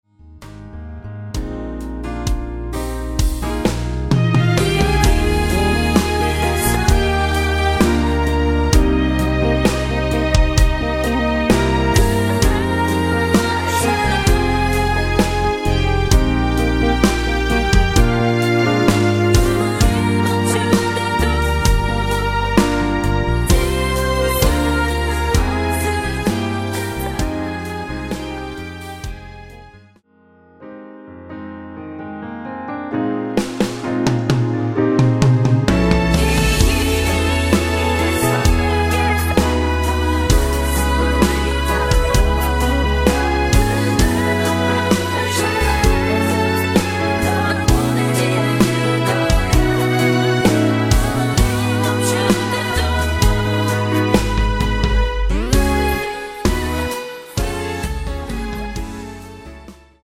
원키 코러스 포함된 MR 입니다.(미리듣기 참조)
Eb
앨범 | O.S.T
앞부분30초, 뒷부분30초씩 편집해서 올려 드리고 있습니다.
중간에 음이 끈어지고 다시 나오는 이유는